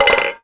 grenade_hit1.wav